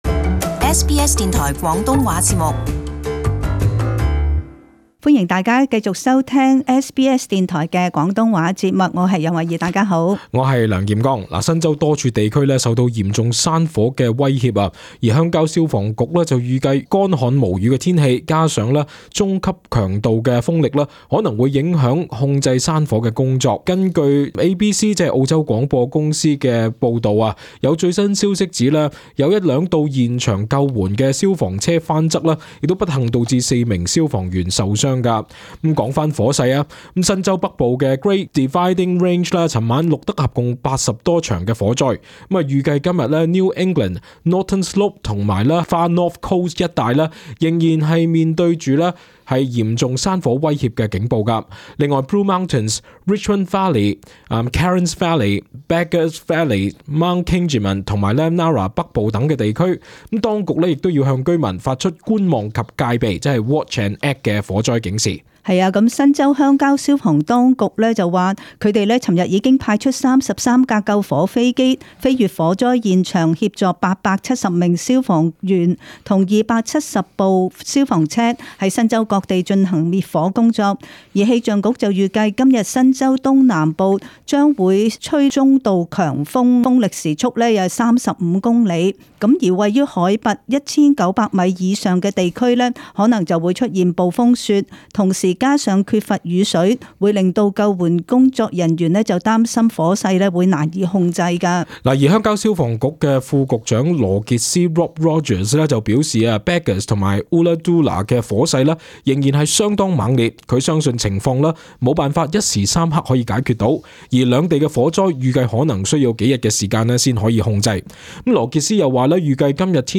【時事報導】新州山火持續 消防局擔心天氣影響救援